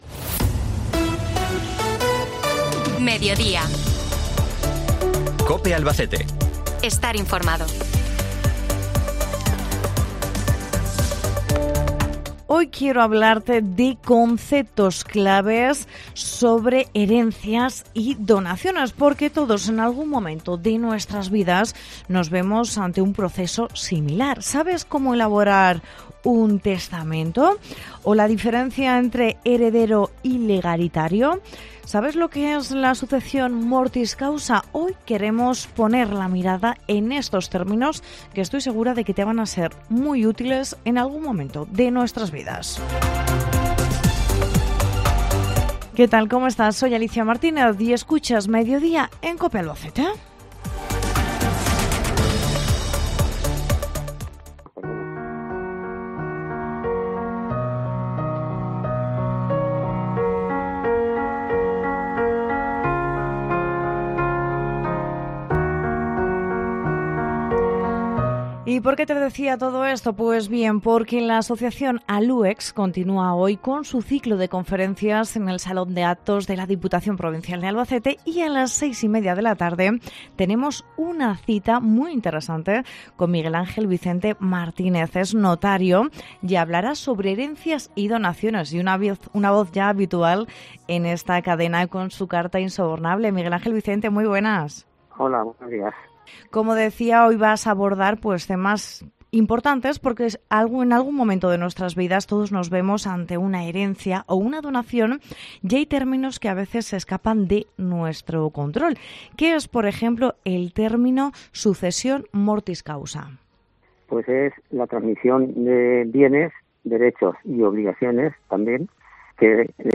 Hablamos de testamentos, donaciones y tramites legales con el notario